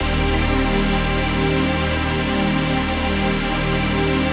TRANCPAD27-LR.wav